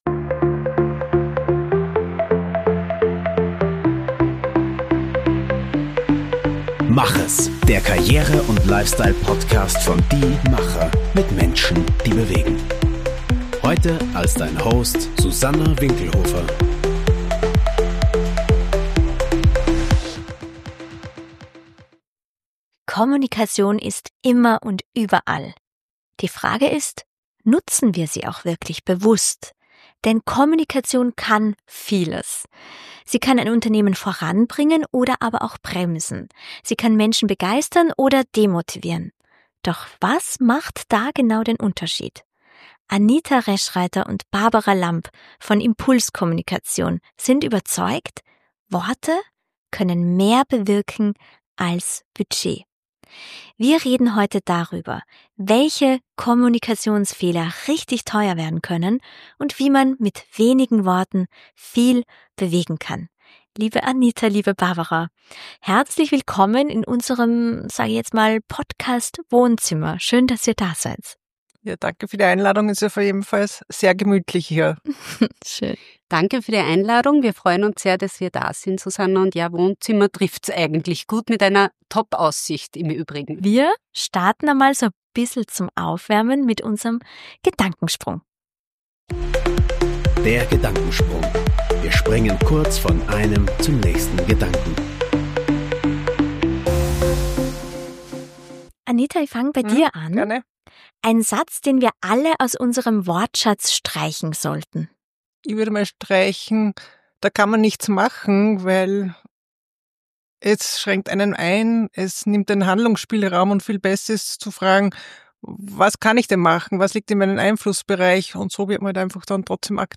In dieser Folge sprechen wir über Kommunikationsfehler, die teuer werden können – und darüber, wie man mit wenigen, gezielten Worten viel bewegen kann. Ein Gespräch über Klarheit, Wirkung und die Kunst, wirklich zuzuhören.